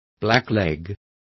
Complete with pronunciation of the translation of blackleg.